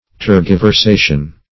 Tergiversation \Ter`gi*ver*sa"tion\, n. [L. tergiversario: cf.
tergiversation.mp3